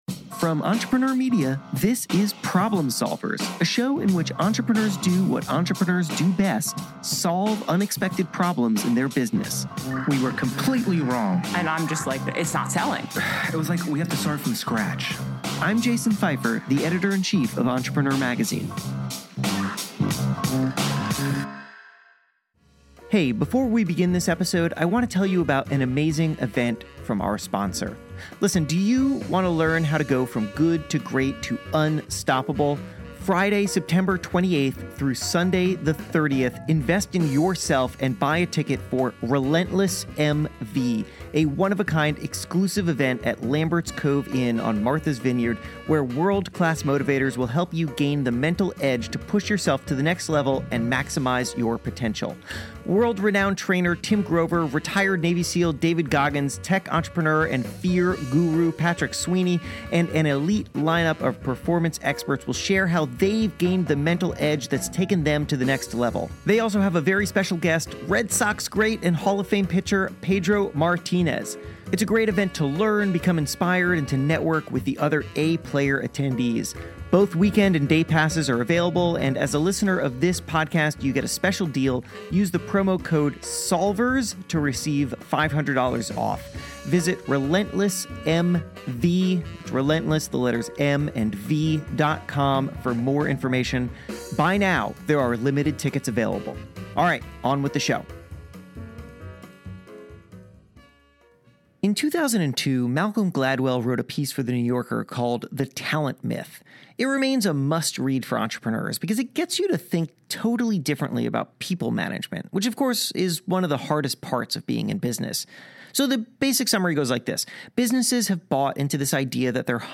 In this conversation with Gladwell, he explains how he approaches problem solving -- by being a hard-to-define outsider.